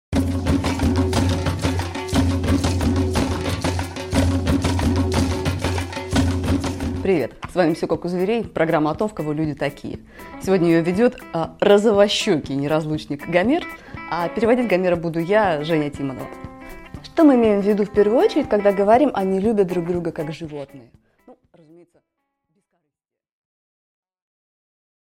Аудиокнига Пингвины легкого поведения | Библиотека аудиокниг